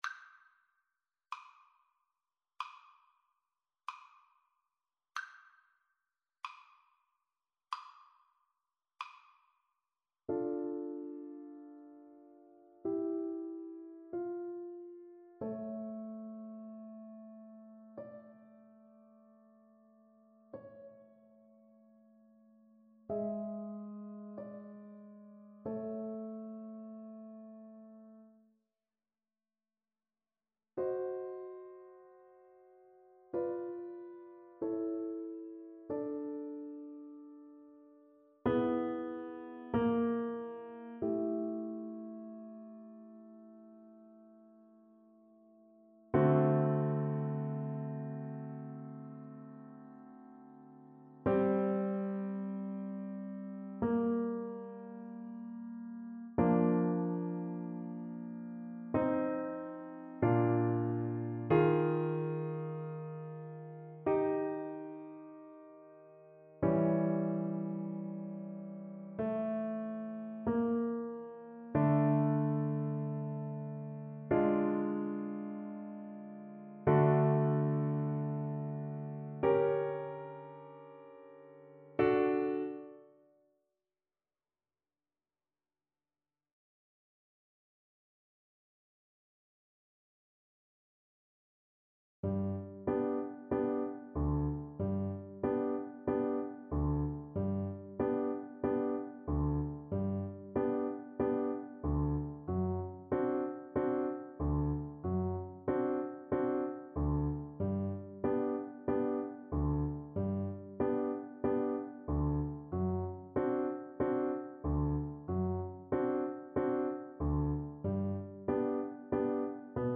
4/4 (View more 4/4 Music)
Classical (View more Classical Flute Music)